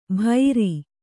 ♪ bhairi